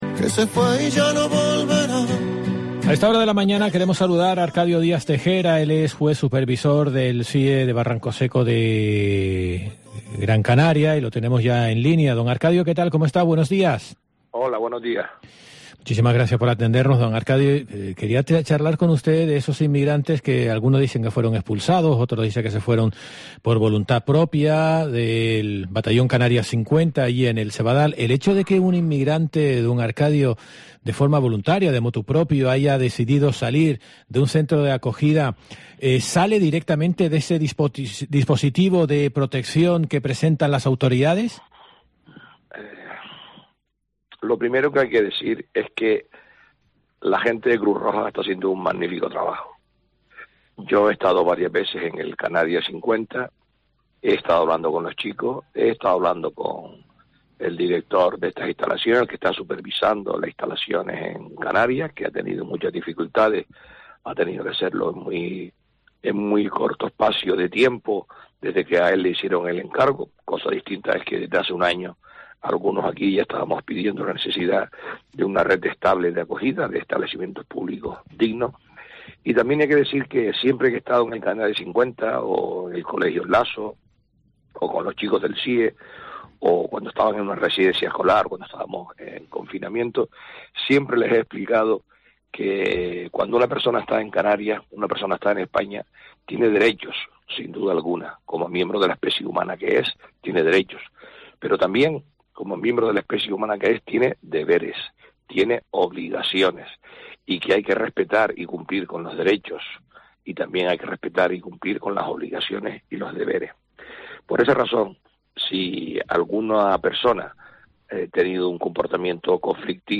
Entrevista Arcadio Díaz Tejera y Pepa Luzardo